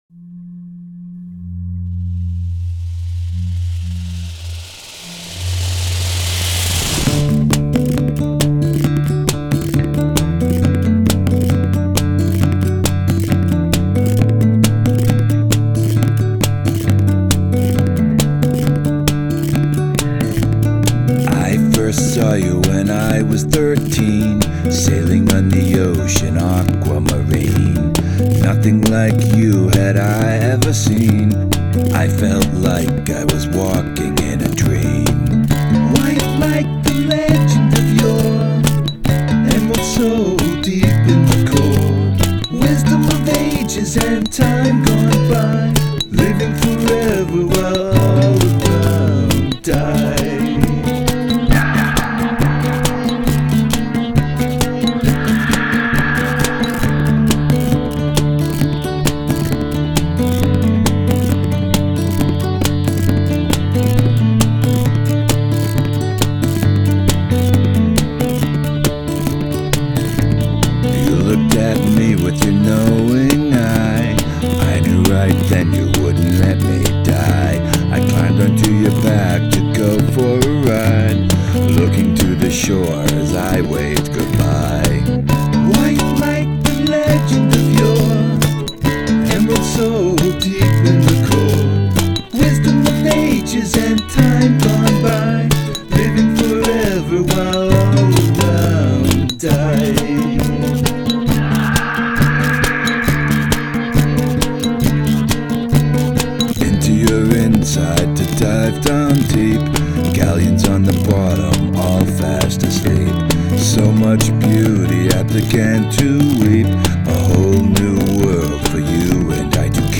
Drums pretty weak under "WHAAAALE!"